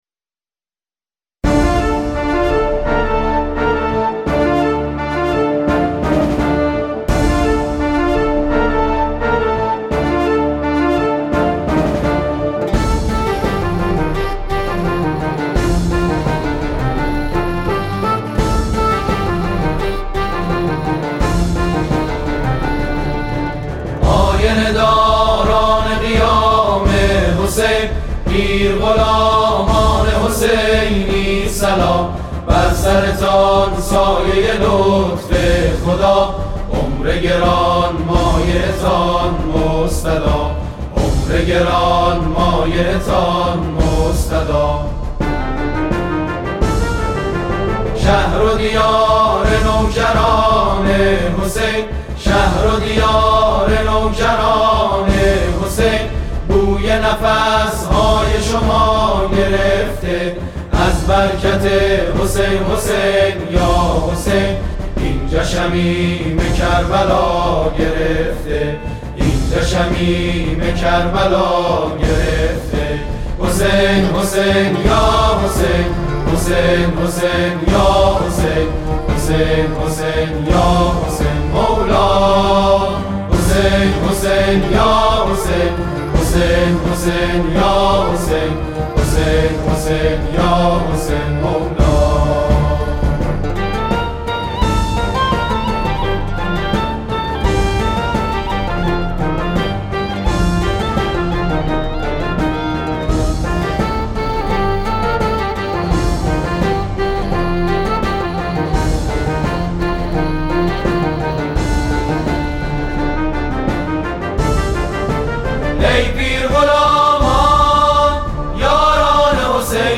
همخوانی شنیدنی گروه مداحان استان فارس برای پیرغلامان حسینی صوت - تسنیم
به گزارش خبرنگار فرهنگی خبرگزاری تسنیم، صبح امروز سیزدهمین اجلاس بین المللی پیرغلامان و خادمان حسینی با حضور مهمانانی از 19 کشور جهان، رسما در شیراز آغاز بکار کرد.
در افتتاحیه این مراسم سرودی توسط گروه مداحان استان فارس برای خوشامدگویی به پیرغلامان خوانده شد که مورد استقبال خادمان حسینی قرار گرفت و احساسات آنها را متبلور کرد.